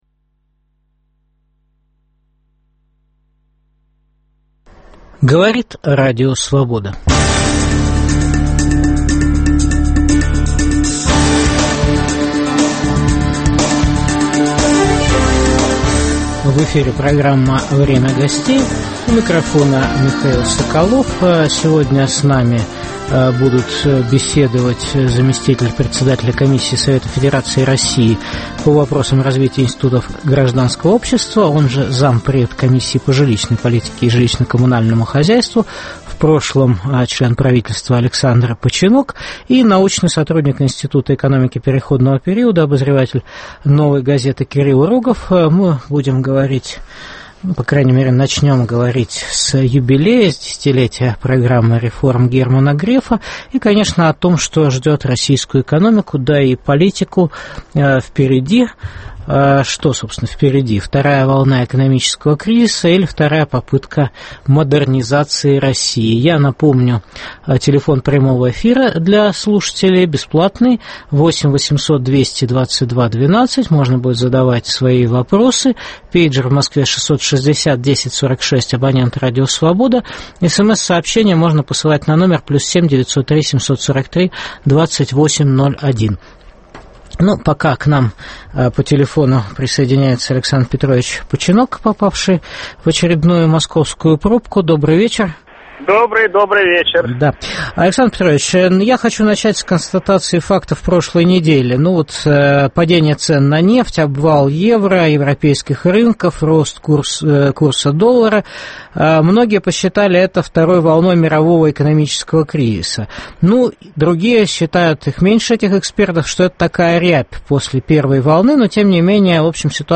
Что впереди: вторая волна экономического кризиса или вторая попытка модернизации России? В студии зам председателя комиссии Совета Федерации России по вопросам развития институтов гражданского общества профессор Александр Починок